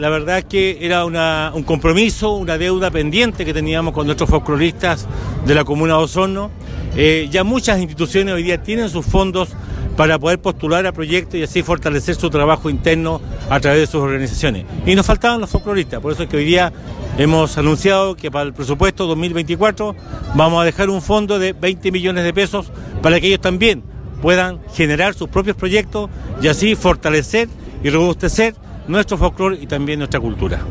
En la ocasión el alcalde Emeterio Carrillo anunció la creación de un nuevo fondo municipal que destinará $20 millones para el desarrollo de los conjuntos folclóricos locales, además de destacar el trabajo de los folcloristas, quienes mostraron lo mejor de nuestras tradiciones ante un masivo público.
04-septiembre-23-emeterio-carrillo-desfile.mp3